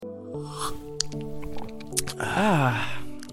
Tags: Cosy Corner Bruits de Bouches rires